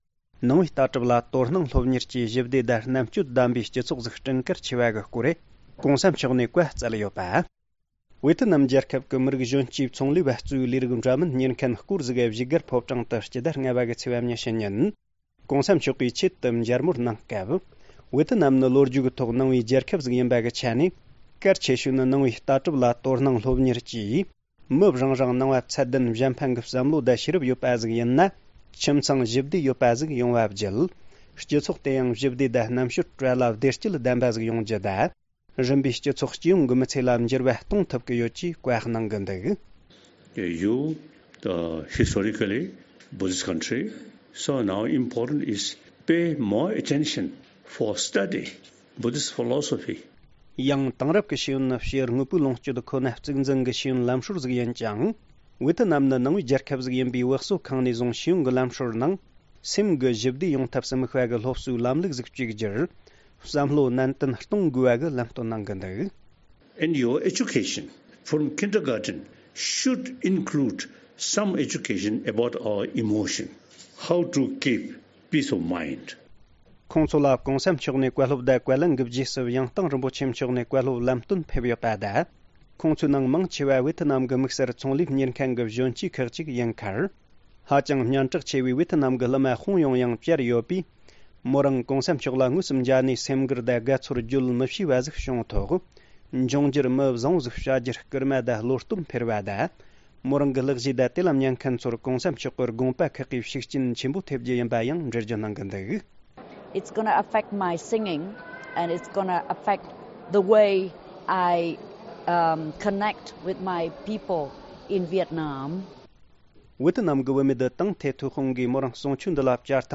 ༧གོང་ས་མཆོག་གིས་ཝིཡེཏ་ནམ་གྱི་མཇལ་བཅར་བ་ཚོར་བཀའ་སློབ་སྩལ་སྐབས། ༢༠༡༩།༥།༢༠ ༧གོང་ས་མཆོག་གིས་ཝིཡེཏ་ནམ་གྱི་མཇལ་བཅར་བ་ཚོར་བཀའ་སློབ་སྩལ་སྐབས། ༢༠༡༩།༥།༢༠
སྒྲ་ལྡན་གསར་འགྱུར།